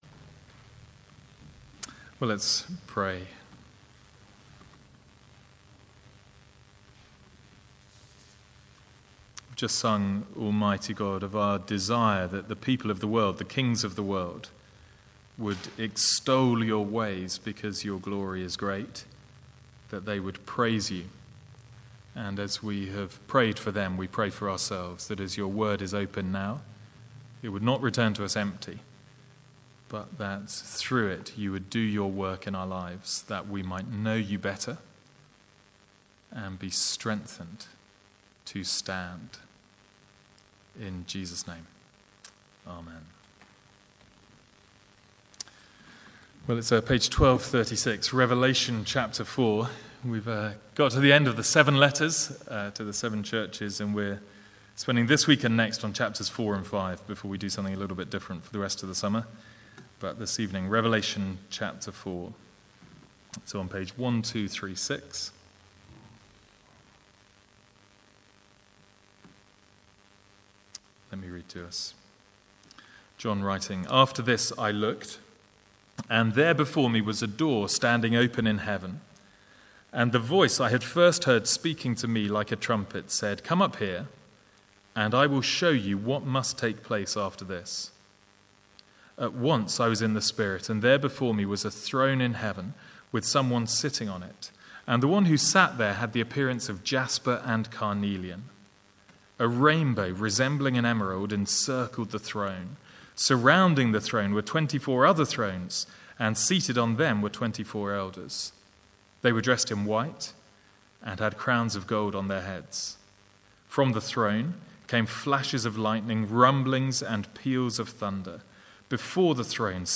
Sermons | St Andrews Free Church
From the Sunday evening series in Revelation.